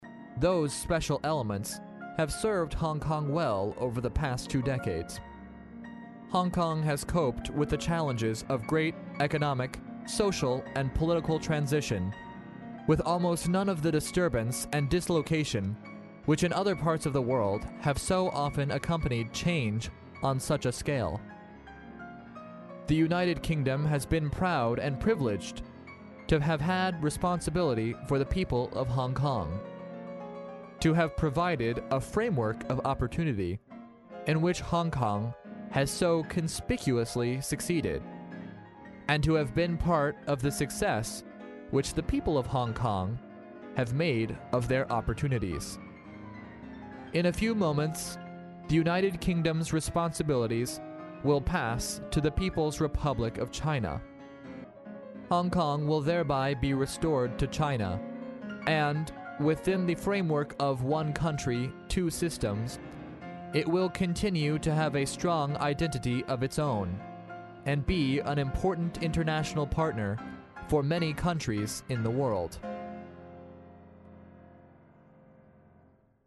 历史英雄名人演讲 第42期:查尔斯王子香港主权交接演讲(3) 听力文件下载—在线英语听力室